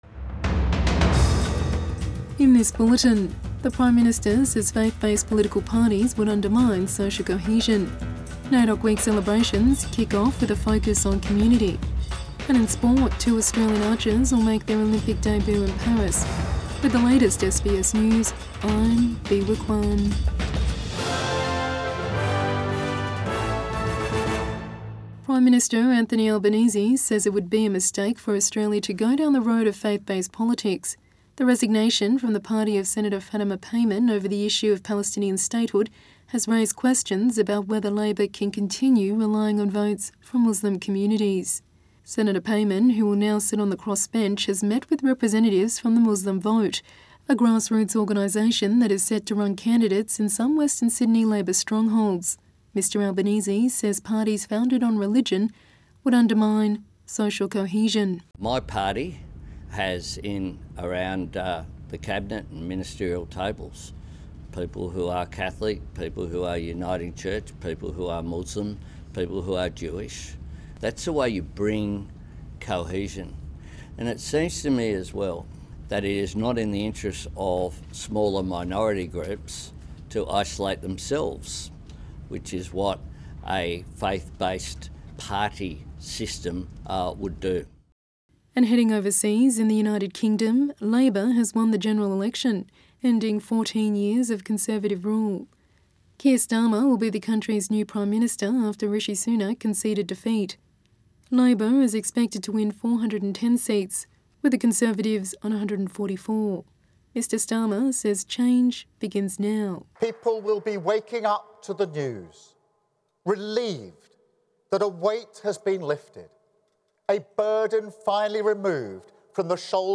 Evening News Bulletin 5 July 2024